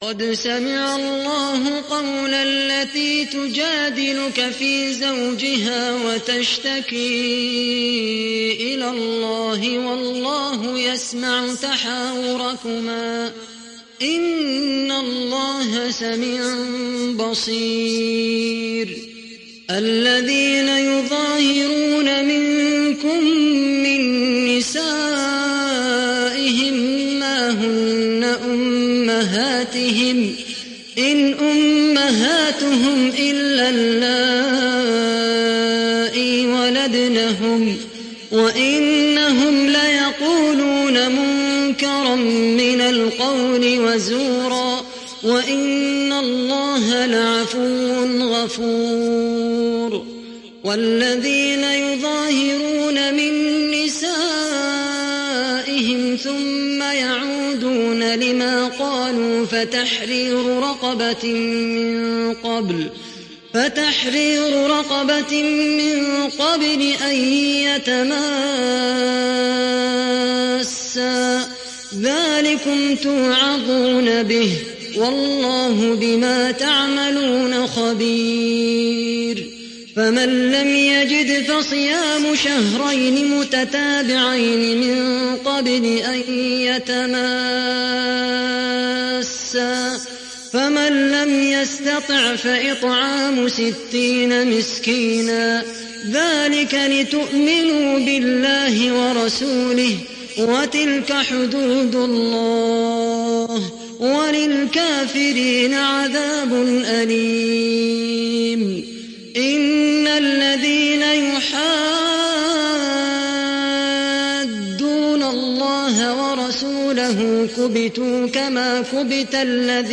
সূরা আল-মুজাদালাহ্ ডাউনলোড mp3 Khaled Al Qahtani উপন্যাস Hafs থেকে Asim, ডাউনলোড করুন এবং কুরআন শুনুন mp3 সম্পূর্ণ সরাসরি লিঙ্ক